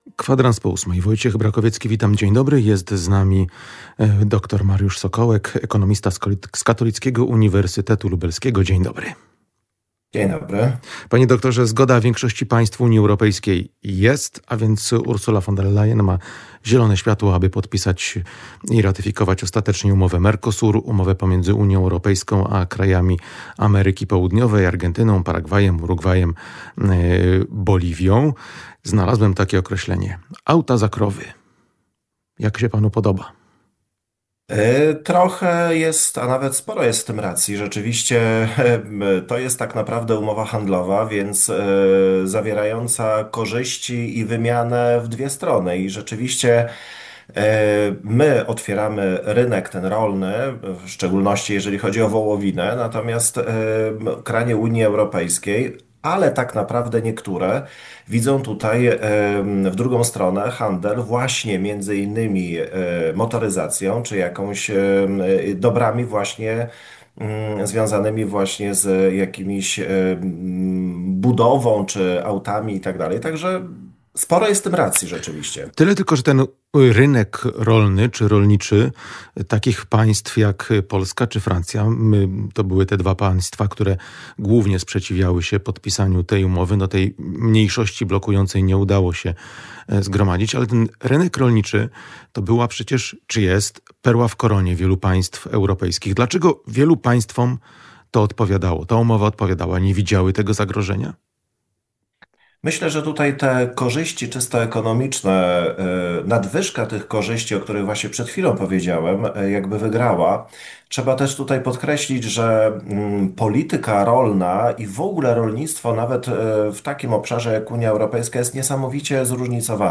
Cała rozmowa w materiale audio: Polski rząd zamierza zgłosić umowę między Unią Europejską a Mercosurem do Trybunału Sprawiedliwości Unii Europejskiej.